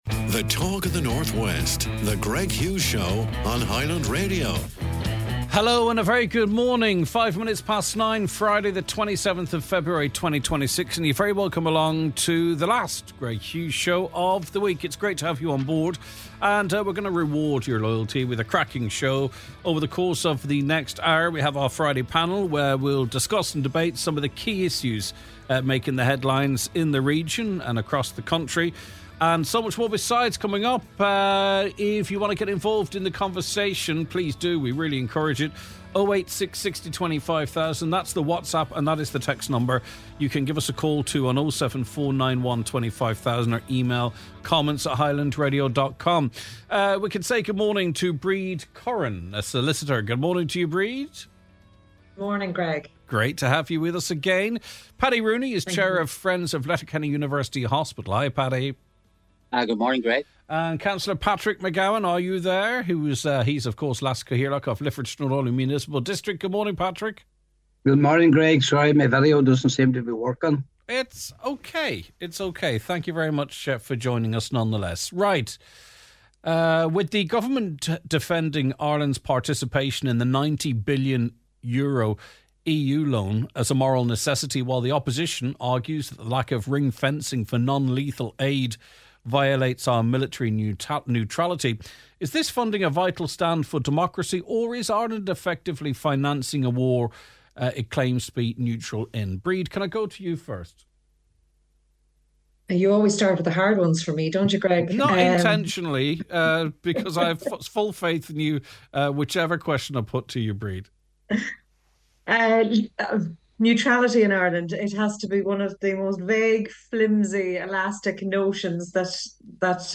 The Friday Panel
On today’s show, we dive deep into the big debates affecting both the nation and the local streets of Donegal, plus a packed lineup of music and community spirit.